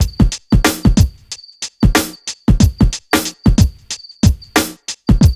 1HF92BEAT1-L.wav